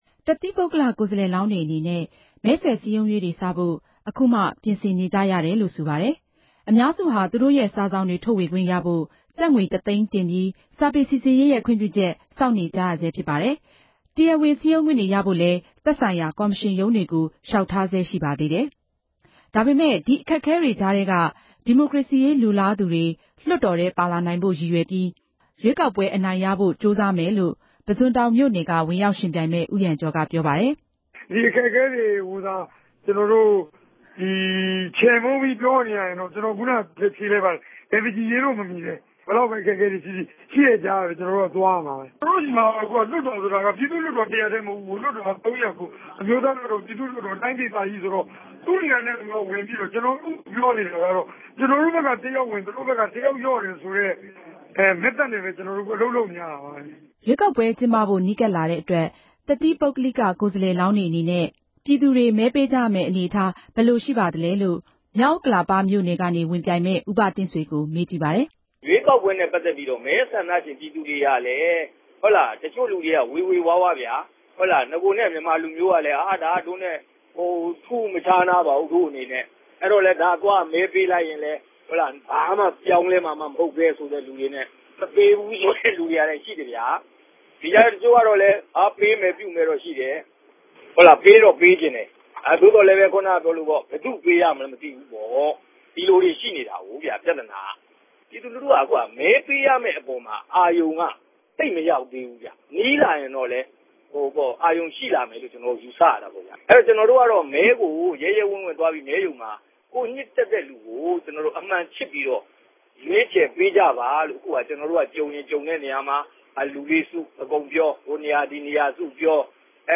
သတင်းတင်ပြချက်။